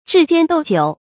注音：ㄓㄧˋ ㄐㄧㄢ ㄉㄡˇ ㄐㄧㄨˇ
彘肩斗酒的讀法